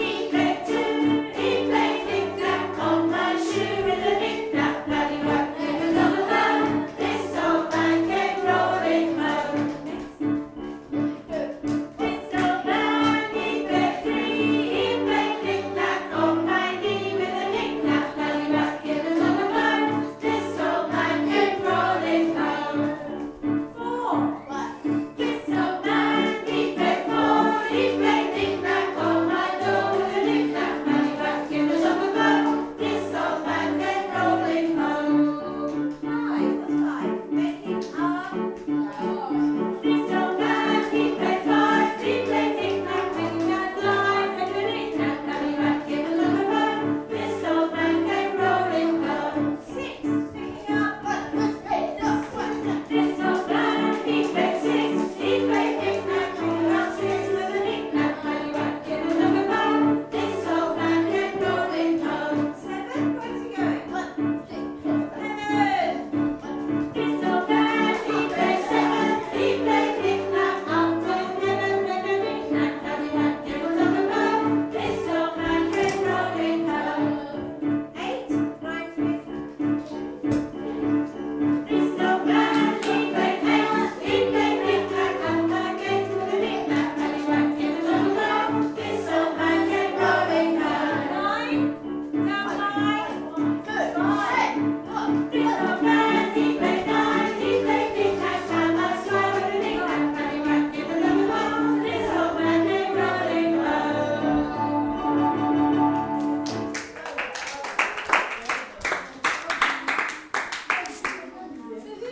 Yellow Class singing